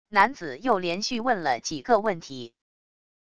男子又连续问了几个问题wav音频生成系统WAV Audio Player